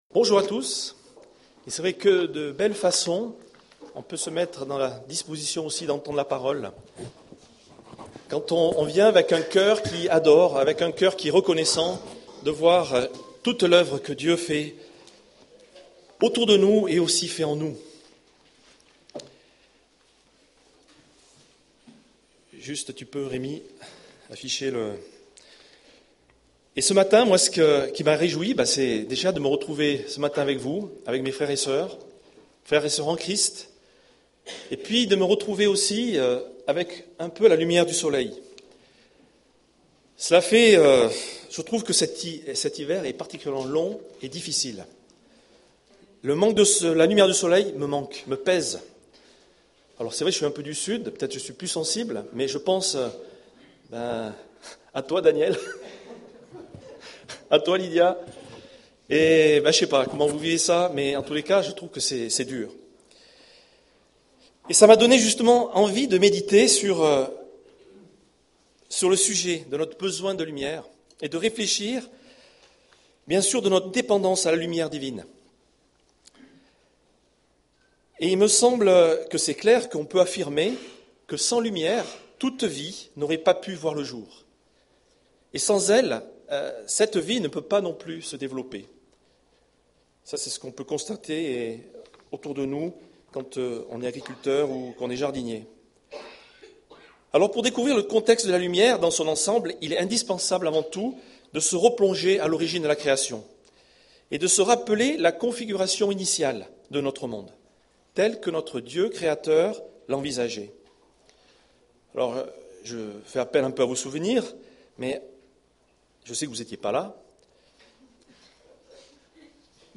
Culte du 24 février